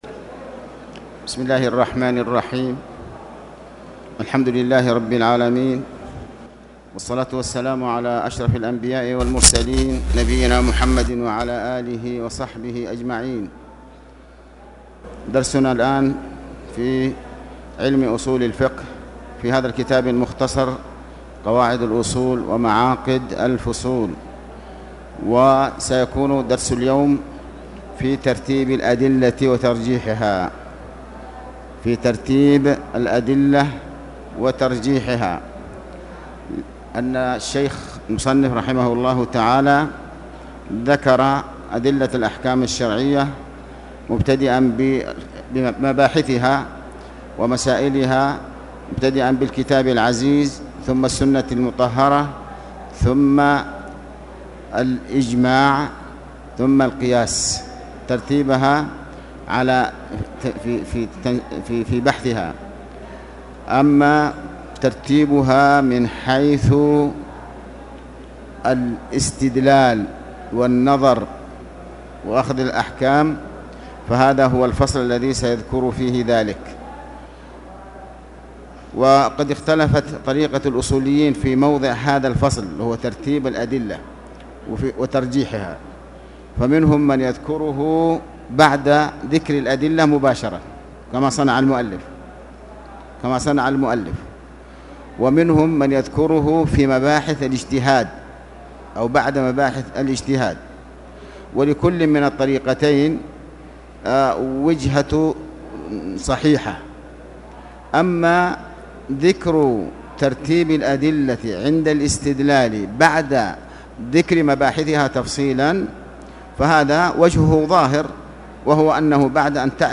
تاريخ النشر ٢٣ جمادى الآخرة ١٤٣٨ هـ المكان: المسجد الحرام الشيخ